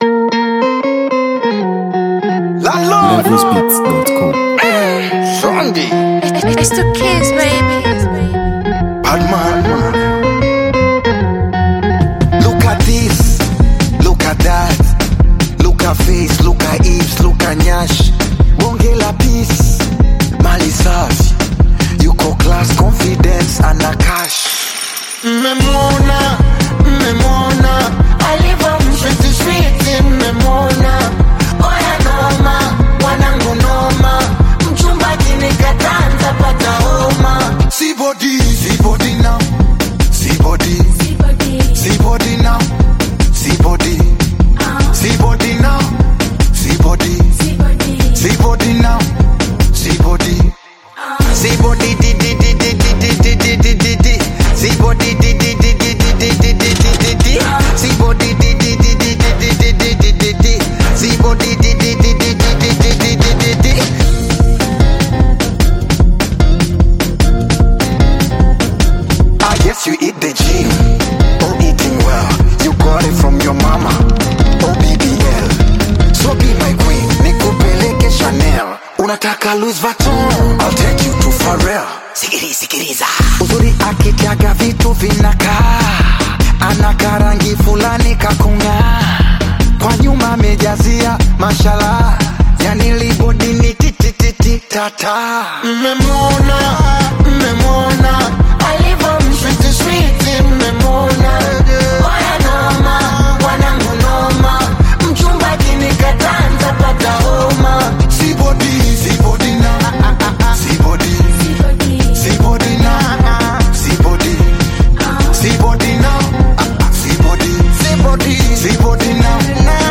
Tanzanian R&B and Afro-pop sensation
silky vocals
groovy, mid-tempo Afrobeat-inspired instrumental
With its feel-good rhythm and charming delivery
Afro-R&B sounds